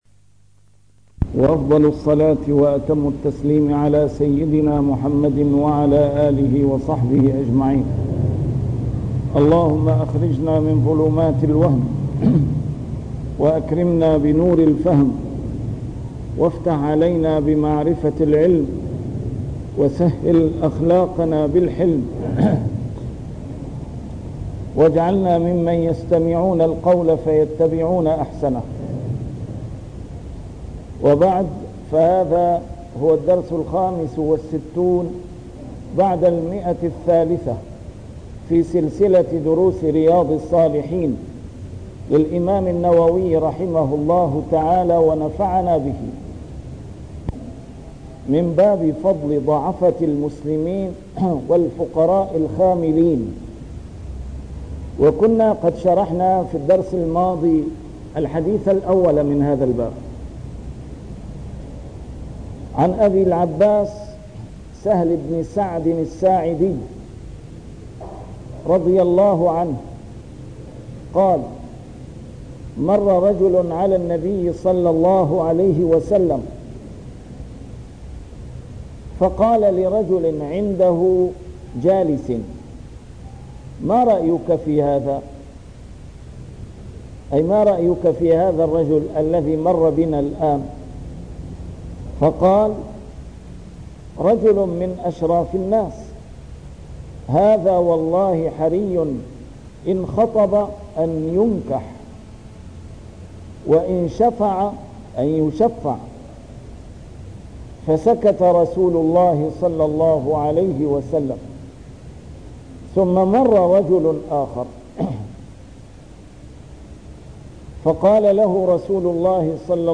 نسيم الشام › A MARTYR SCHOLAR: IMAM MUHAMMAD SAEED RAMADAN AL-BOUTI - الدروس العلمية - شرح كتاب رياض الصالحين - 365- شرح رياض الصالحين: فضل ضعفة المسلمين